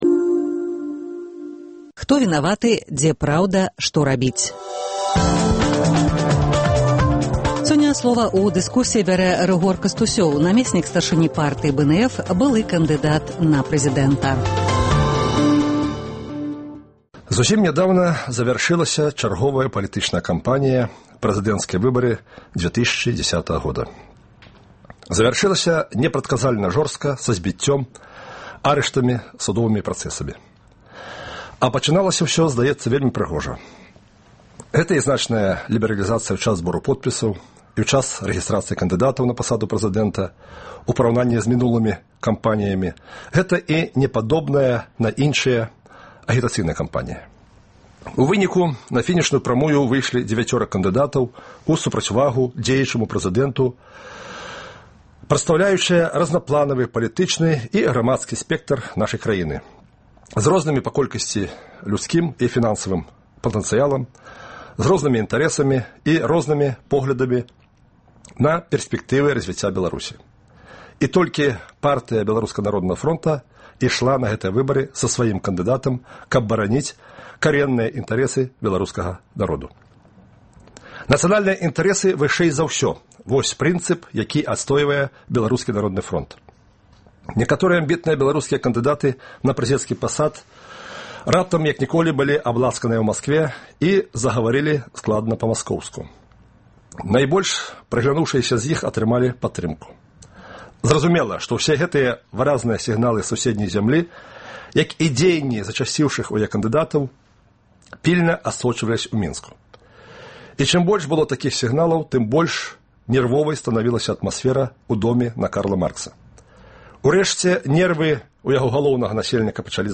За такімі пытаньнямі пасьля 19 сьнежня "Свабода" зьвярнулася да ўдзельнікаў прэзыдэнцкай кампаніі, лідэраў палітычных партыяў, рухаў, грамадзкіх арганізацыяў. У дыскусіі ўдзельнічае намесьнік старшыні Партыі БНФ, былы кандыдат у прэзыдэнты Рыгор Кастусёў.